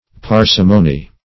Parsimony \Par"si*mo*ny\, n. [L. parsimonia, parcimonia; cf.